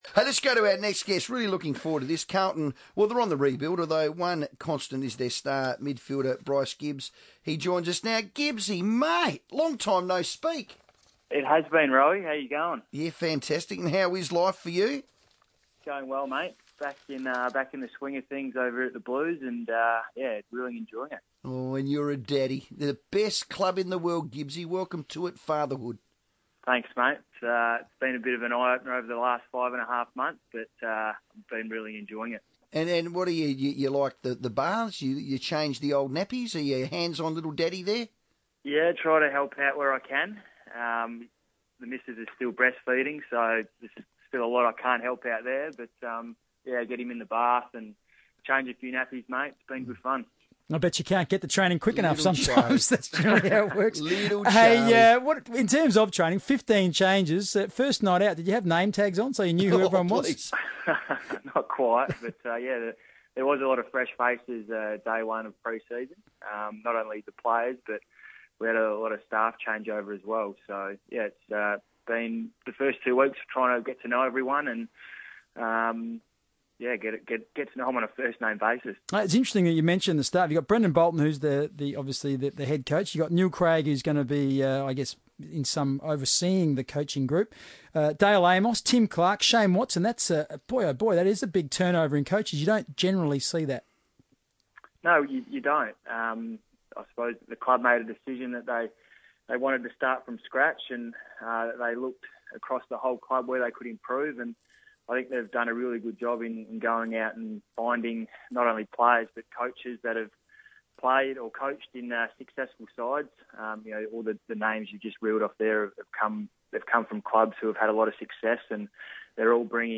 Carlton midfielder Bryce Gibbs catches up with FIVEaa for a chat about pre-season.